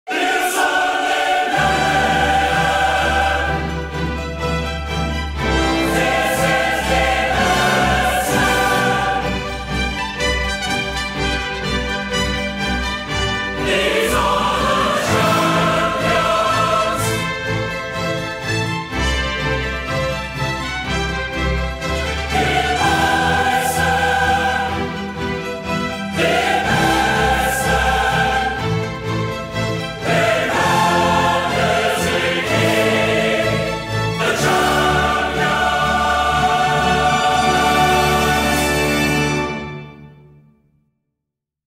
Музыка с необычной атмосферой